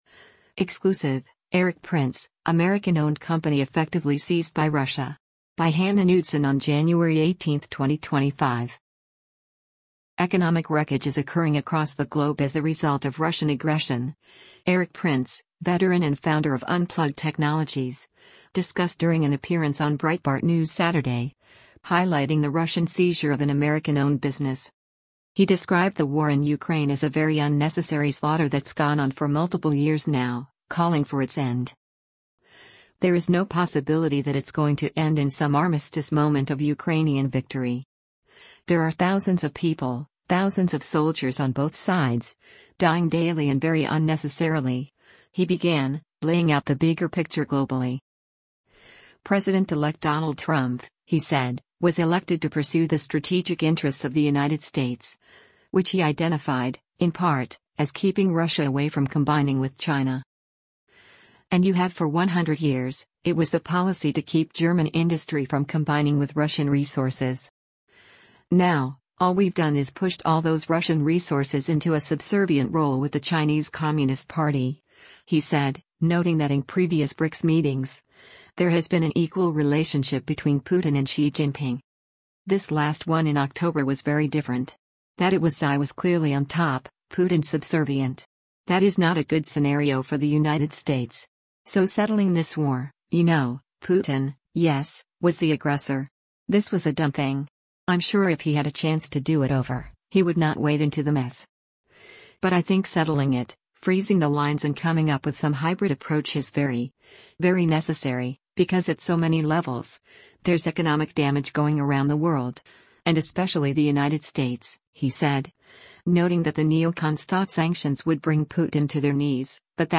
Economic “wreckage” is occurring across the globe as the result of Russian aggression, Erik Prince, veteran and founder of Unplugged Technologies, discussed during an appearance on Breitbart News Saturday, highlighting the Russian seizure of an American-owned business.